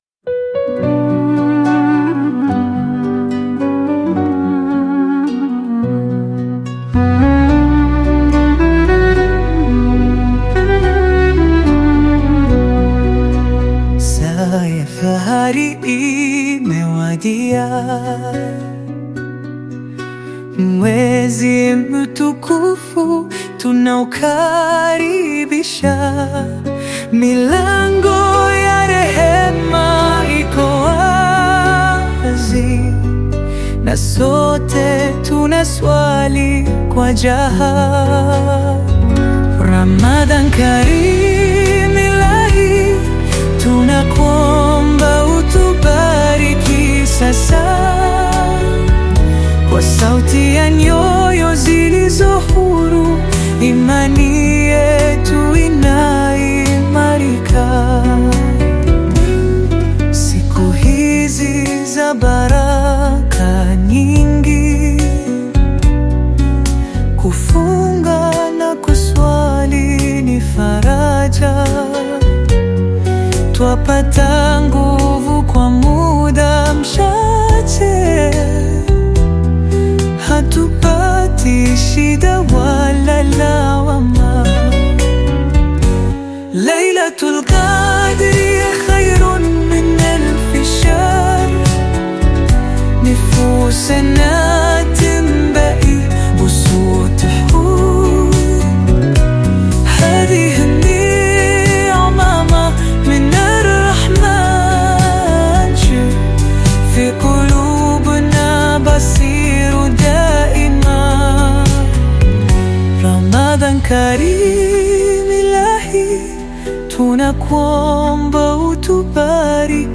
Qaswida You may also like